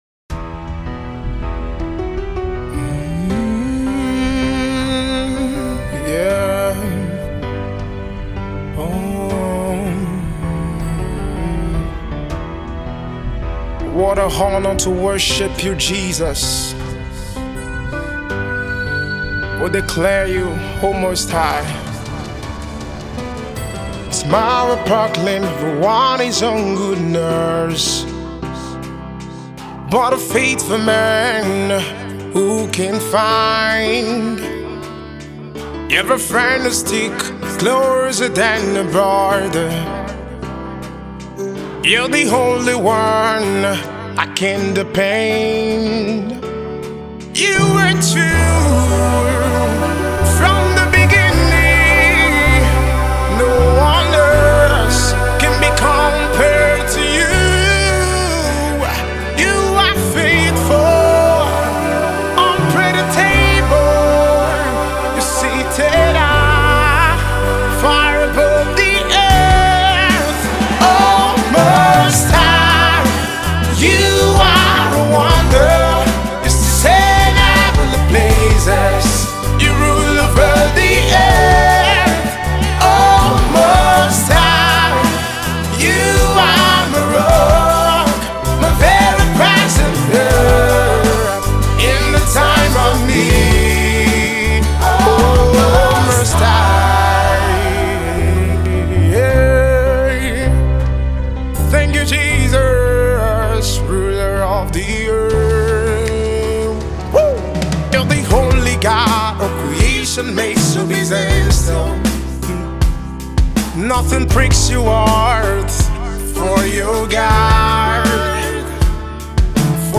gospel
a song of praise to the Lord Jesus